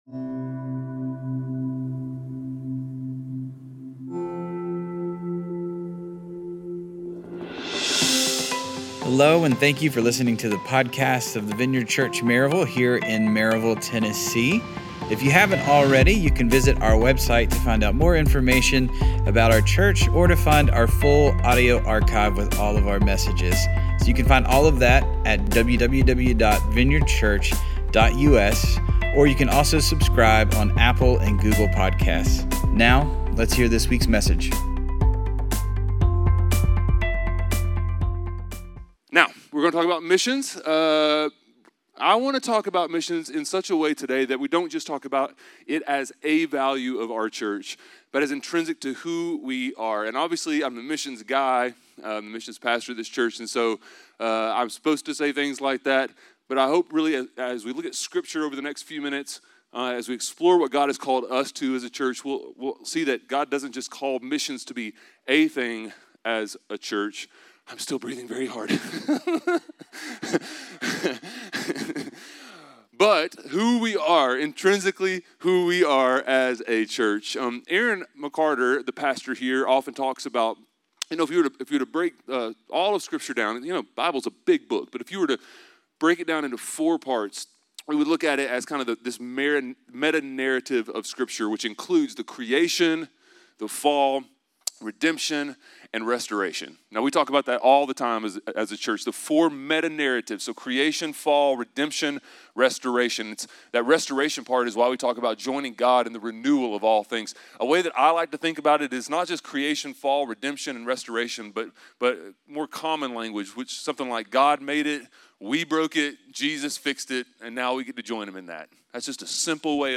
A message from the series "Core Values."